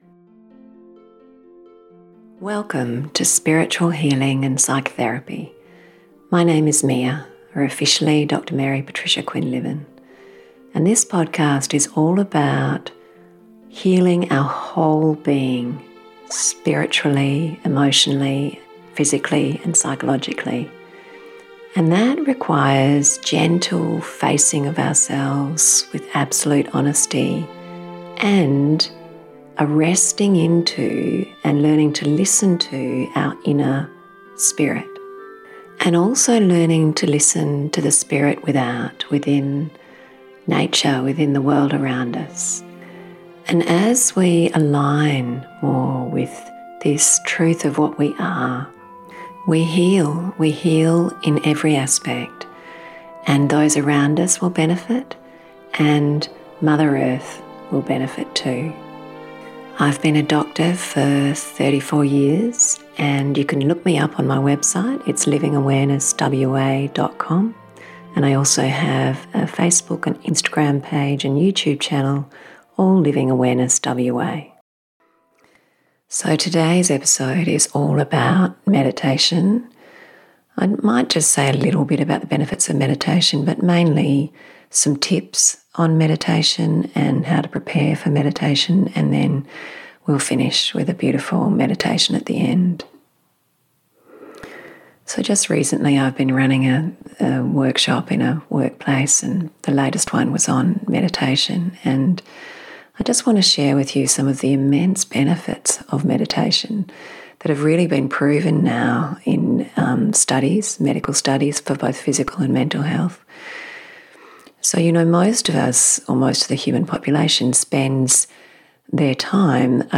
This podcast covers the immense physical, emotional and spiritual benefits of meditation. Learn how to make meditation work for you with tips and practical advice. Then practice a beautiful 10 minute meditation.